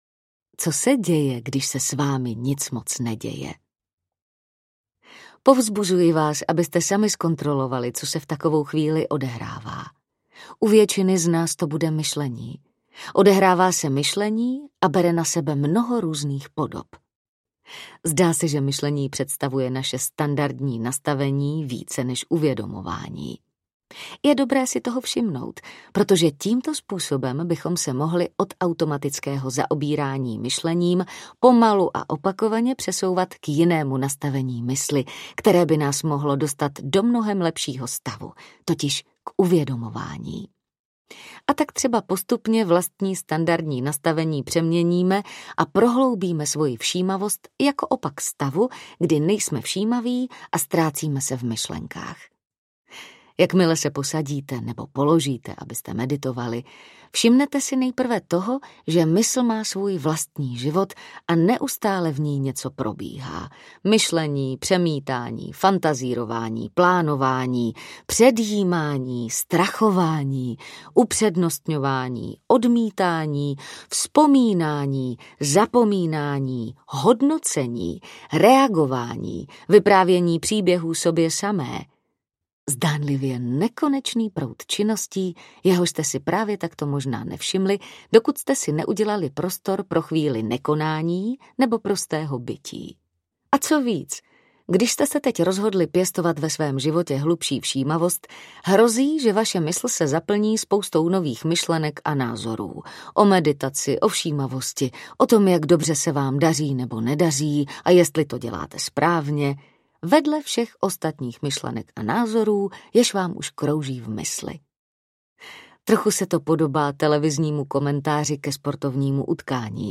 Mindfulness pro začátečníky audiokniha
Ukázka z knihy